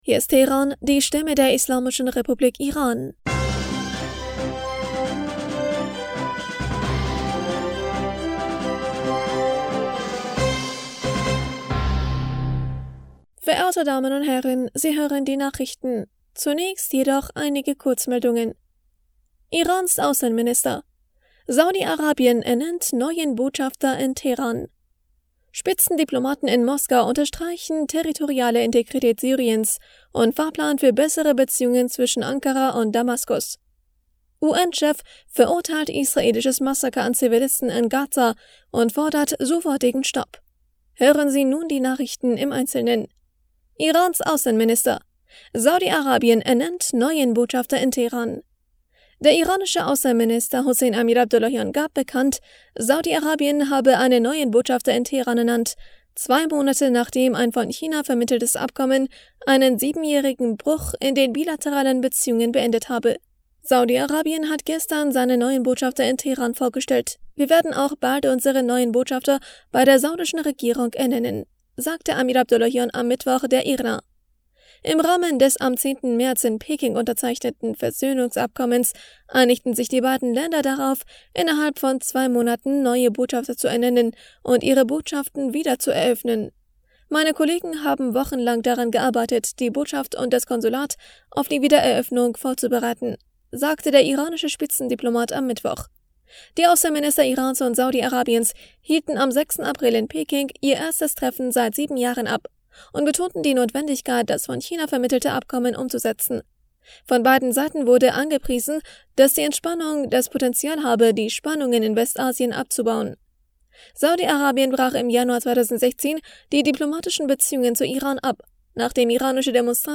Nachrichten vom 11. Mai 2023